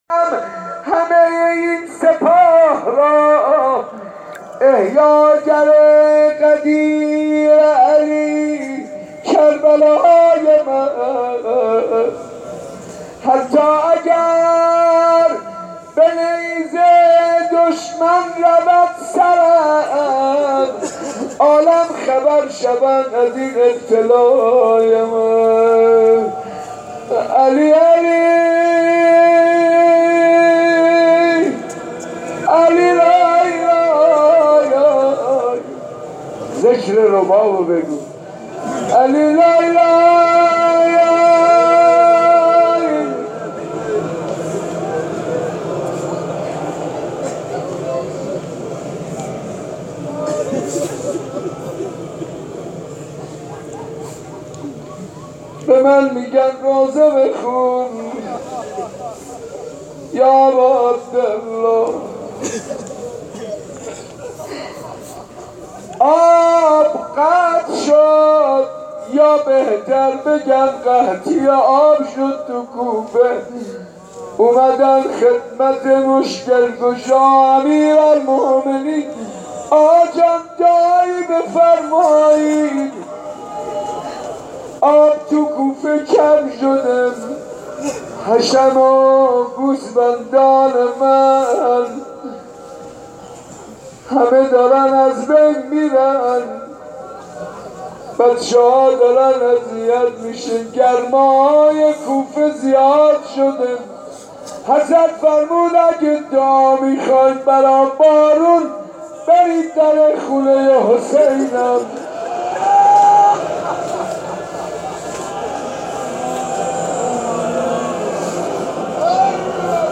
عقیق : هفتمین شب از مراسم عزاداری سیدالشهدا در مسجد ارک تهران برگزار شد و هزاران نفر برای شیرخوار کربلا اشک ریختند.
صوت مراسم